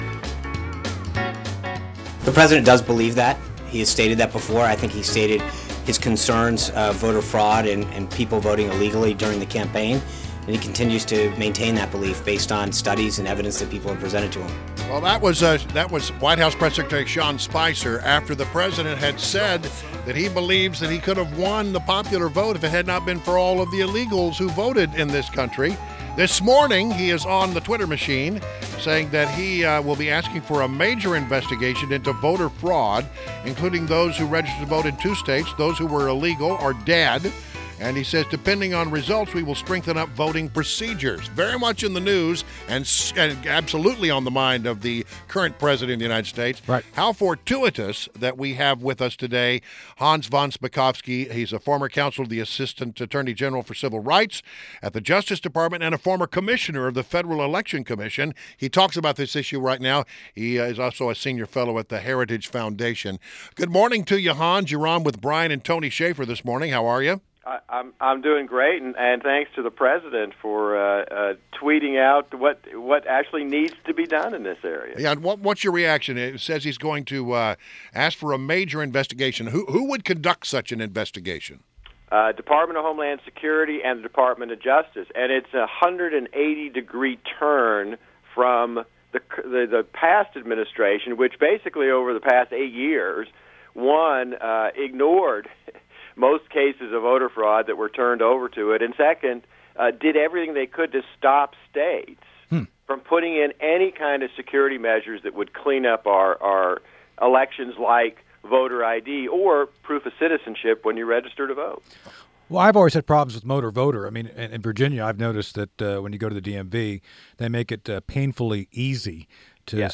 INTERVIEW – HANS VON SPAKOVSKY- former counsel to the assistant attorney general for civil rights in the Justice Department, former commissioner for the Federal Election Commission from 2006 to 2007 and senior fellow at the Heritage Foundation – discussed Trump’s claims about voter fraud.